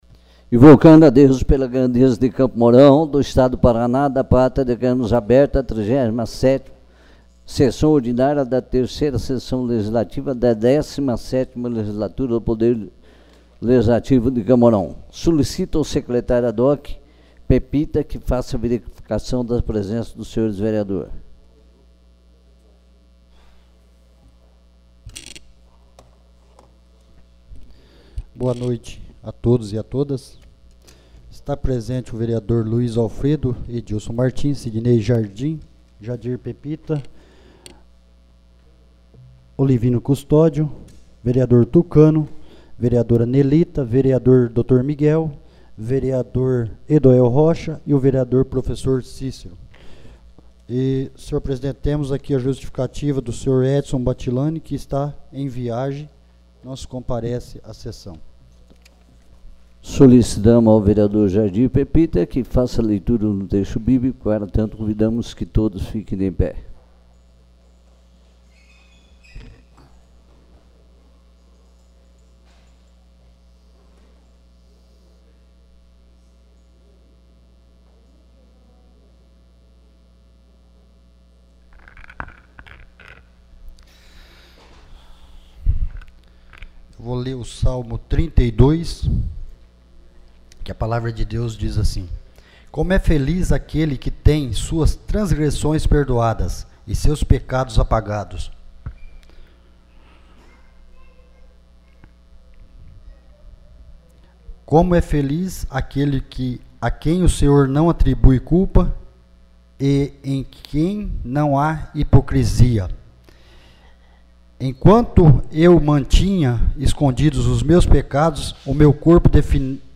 37ª Sessão Ordinária